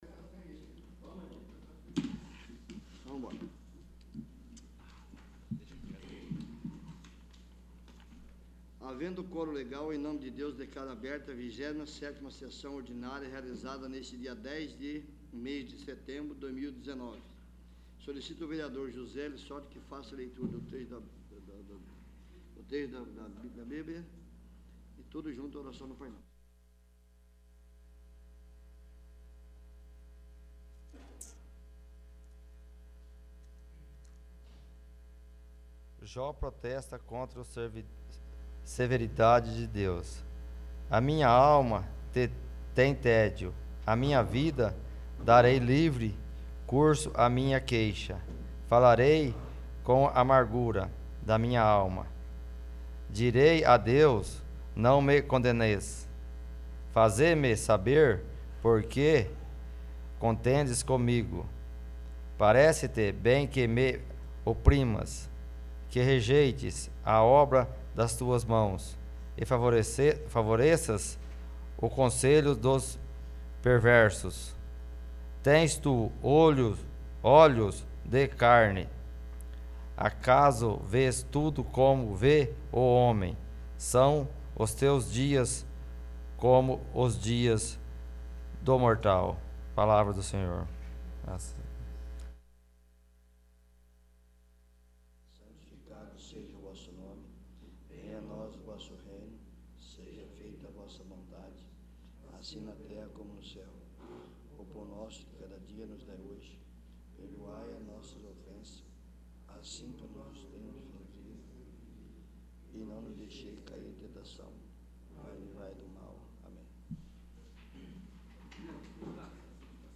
27º. Sessão Ordinária